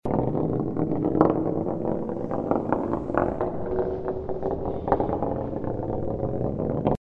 На этой странице собраны звуки мяча в разных ситуациях: удары, отскоки, броски.
Звук катящегося металлического шара по деревянному столу